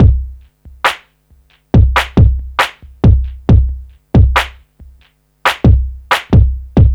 C E.BEAT 4-R.wav